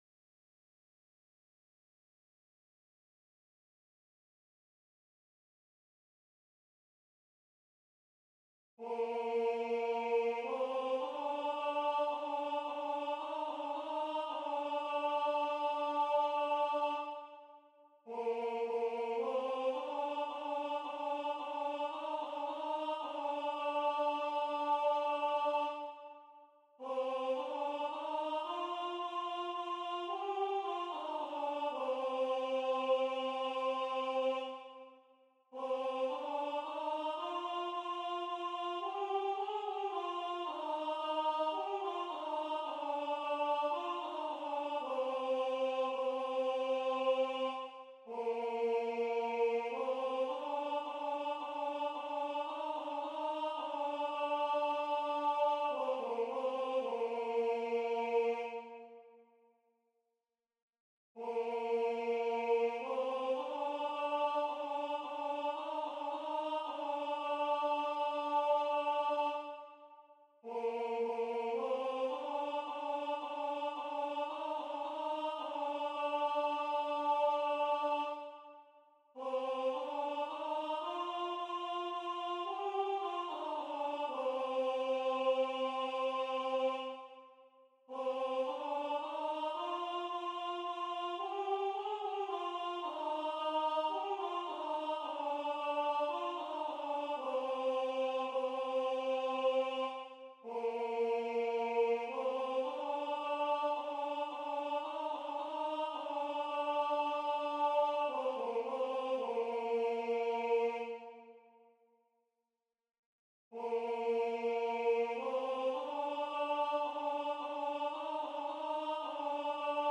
à 3 voix mixtes
MP3 rendu voix synth.
Alto